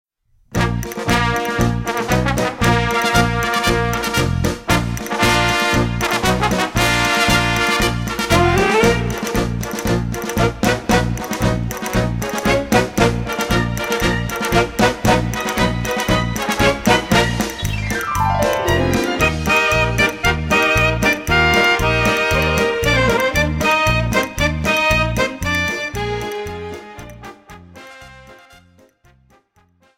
PASO-DOBLE